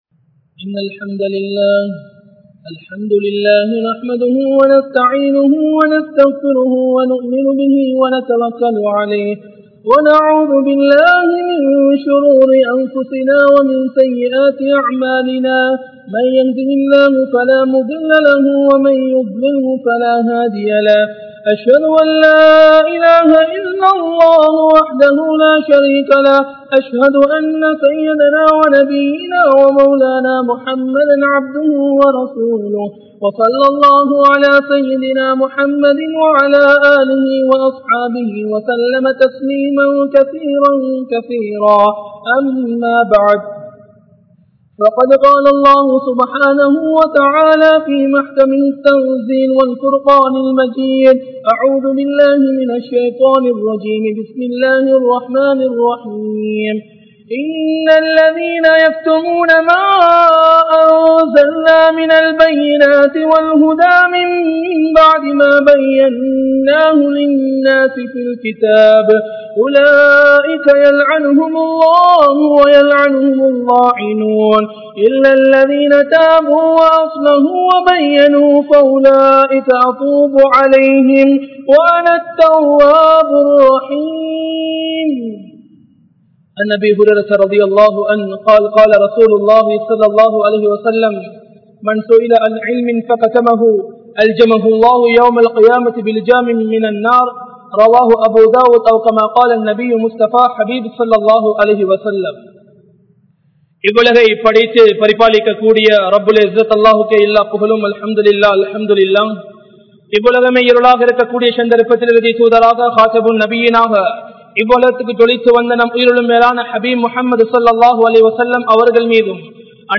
Allah`vin Saafaththai Tharum 07 Paavangal (அல்லாஹ்வின் சாபத்தை தரும் 07 பாவங்கள்) | Audio Bayans | All Ceylon Muslim Youth Community | Addalaichenai
Muhiyadeen Jumua Masjidh